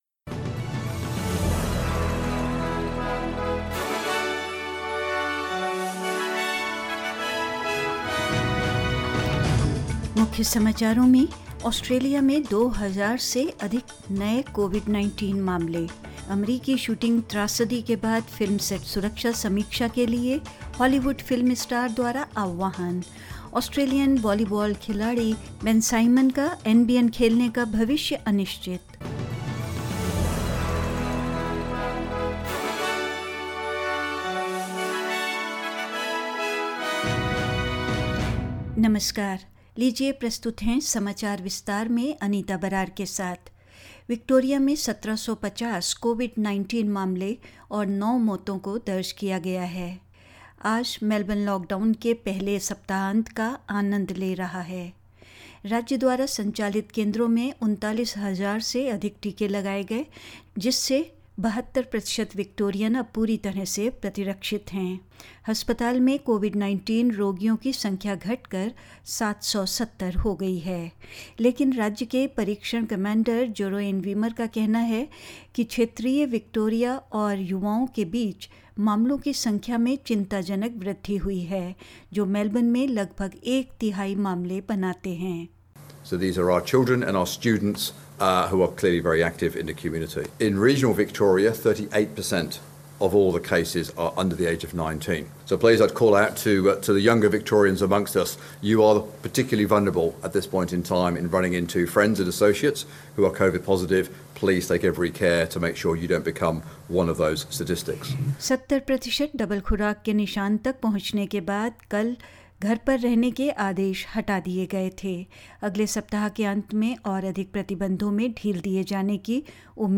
In this latest SBS Hindi News bulletin of Australia and India: More than two thousand new Australian COVID-19 cases reported as Victorians enjoy more eased restrictions; Calls for a film set safety review as a Hollywood film star speaks out over a US shooting tragedy; Indian home minister Amit Shah is on a three-day visit to Jammu and Kashmir and more news.